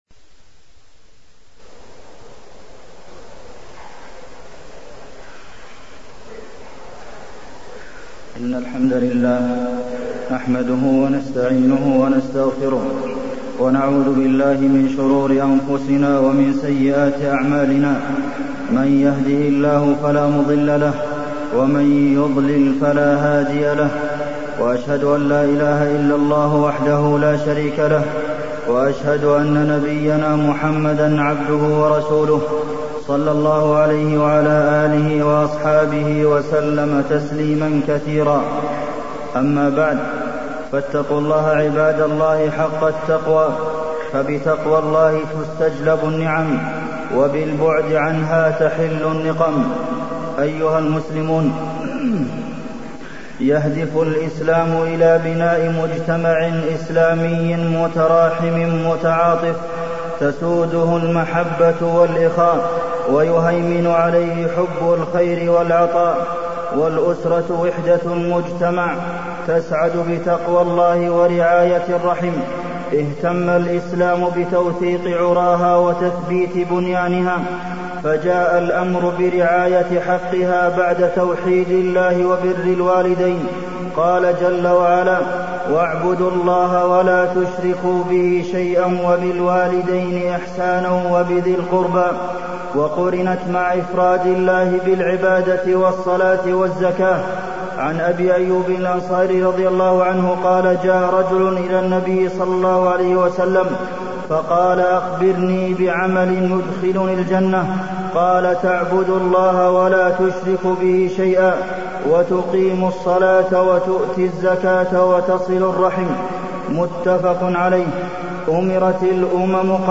تاريخ النشر ٣ ذو القعدة ١٤٢٤ هـ المكان: المسجد النبوي الشيخ: فضيلة الشيخ د. عبدالمحسن بن محمد القاسم فضيلة الشيخ د. عبدالمحسن بن محمد القاسم صلة الرحم